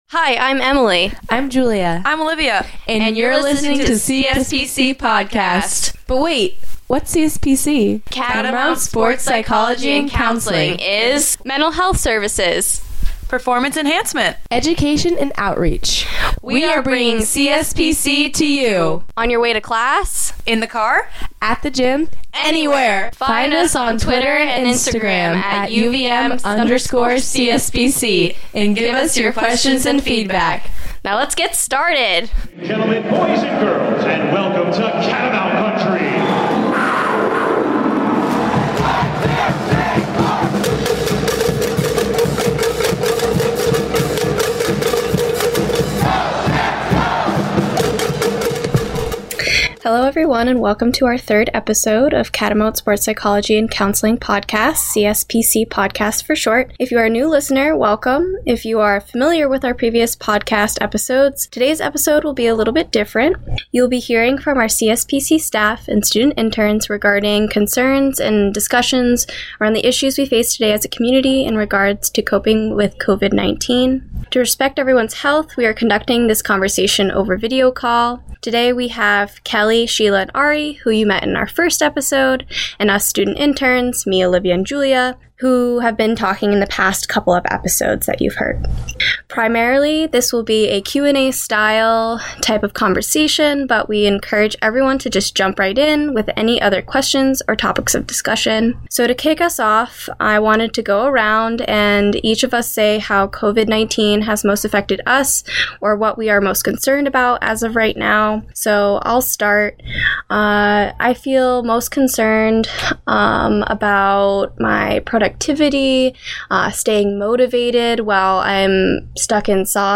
CSPC staff & interns talk about the impact of COVID-19 & coping strategies to get through this together.